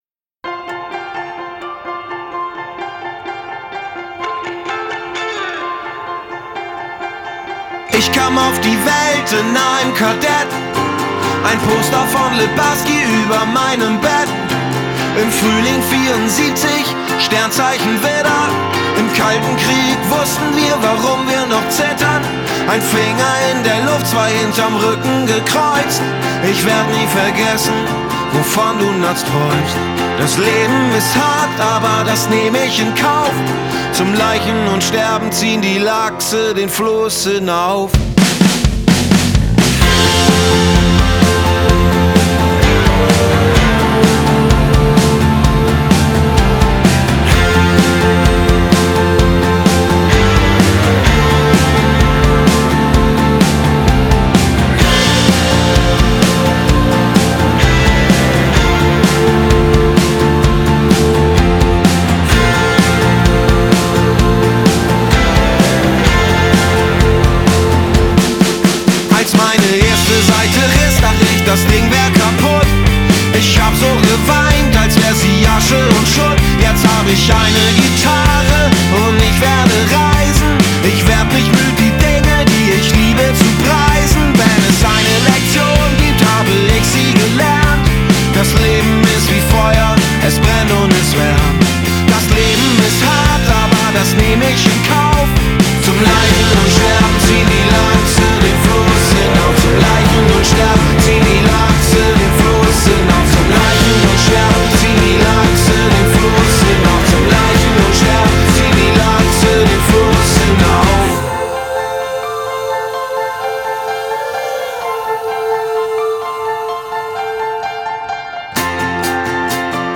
piano
pumps up the poprock as the song develops